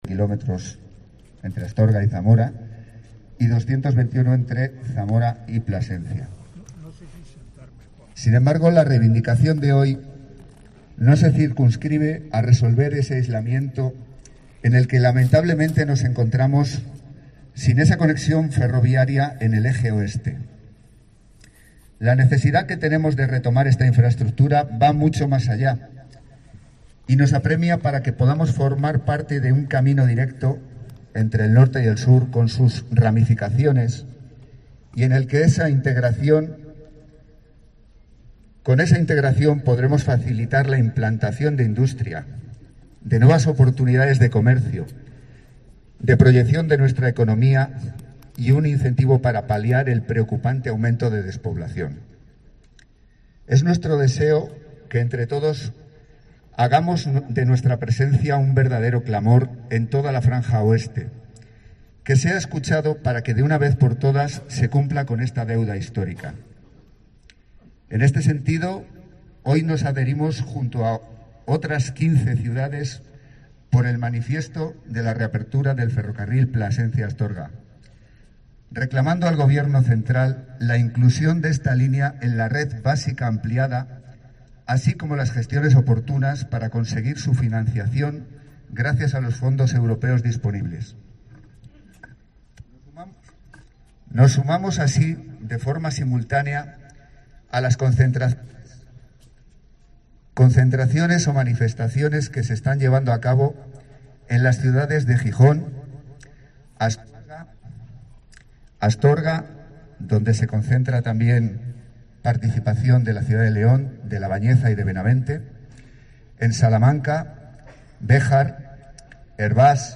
Cerca de 300 personas desafiaron este fin de semana a la insistente lluvia en Zamora para reivindicar la recuperación del corredor ferroviario de la Ruta de la Plata, en respuesta a la convocatoria formulada por el Corredor Oeste y auspiciada por la Asociación Ferroviaria Zamorana.